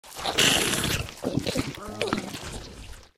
fracture_eat_0.ogg